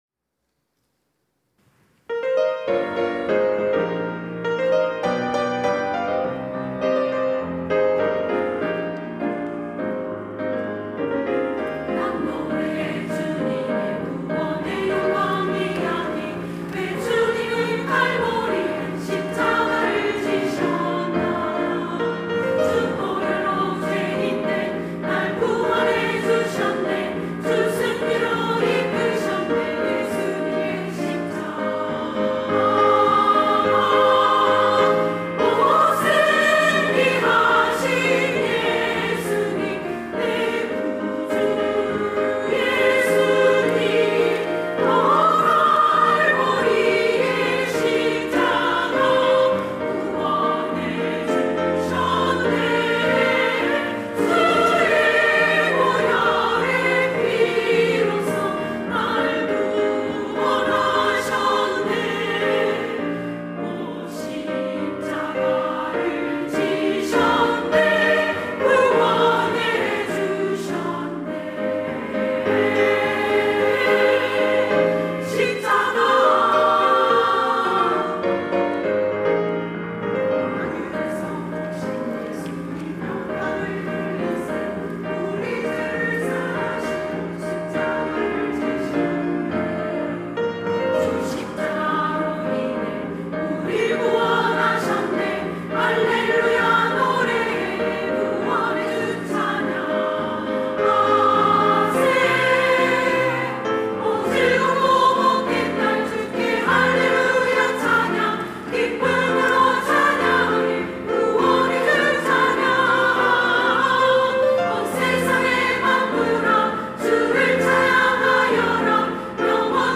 여전도회 - 승리하신 예수님
찬양대